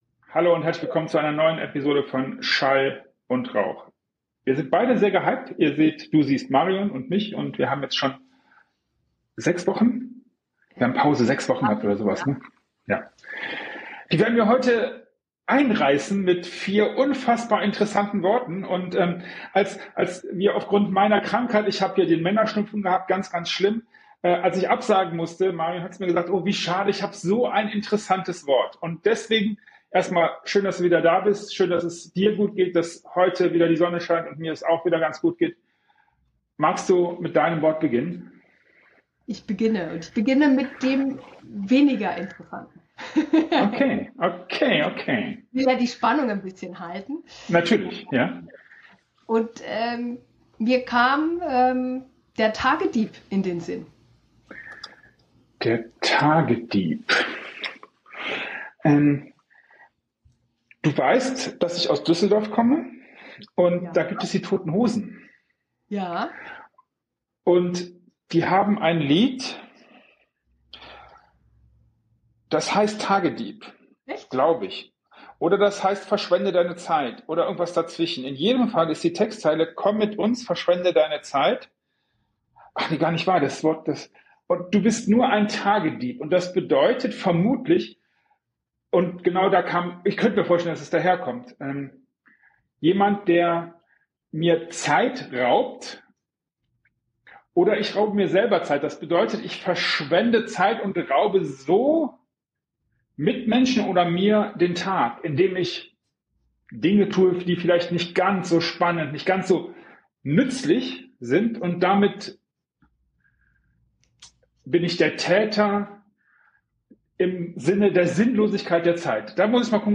Unsere Konversation ist lebhaft, voller Scherze und tiefgründiger Überlegungen.